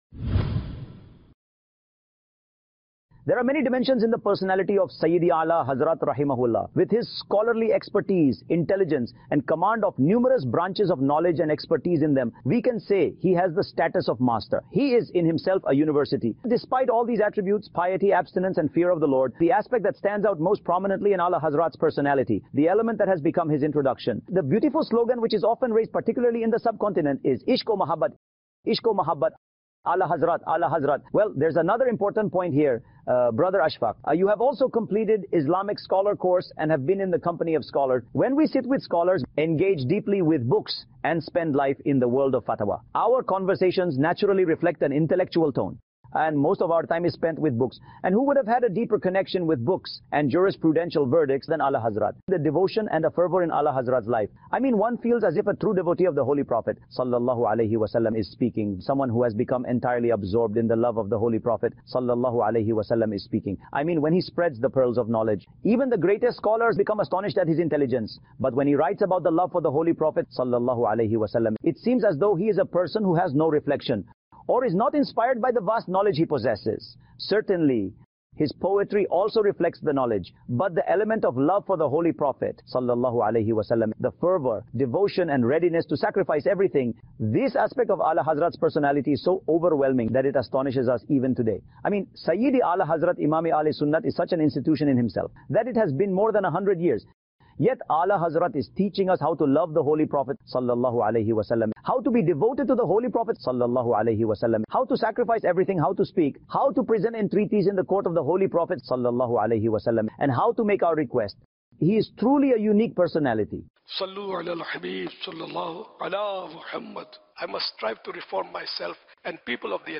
A'la Hazrat رحمۃ اللہ علیہ Devotion To The Holy Prophet صلی اللہ علیہ واٰلہ وسلم (AI-Generated) Sep 26, 2024 MP3 MP4 MP3 Share A'la Hazrat رحمۃ اللہ علیہ Devotion To The Holy Prophet صلی اللہ علیہ واٰلہ وسلم (AI-Generated)